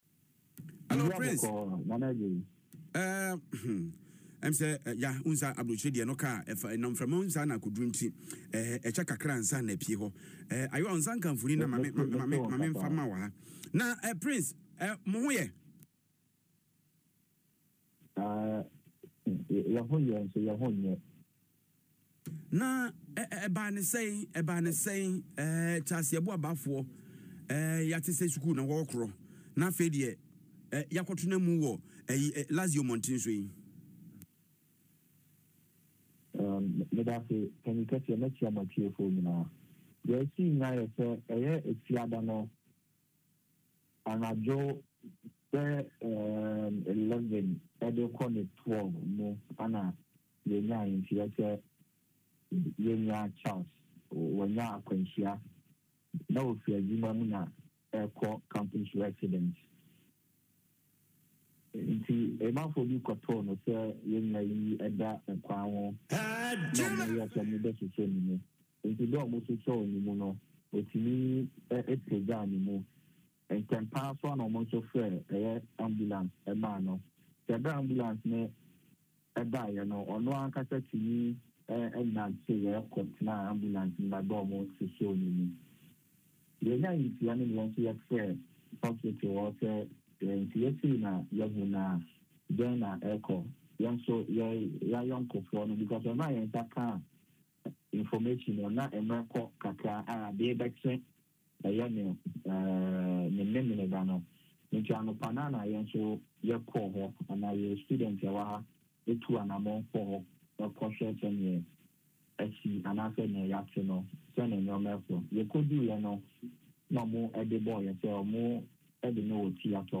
confirmed the news in an interview on Adom FM’s Kasiebo is Tasty.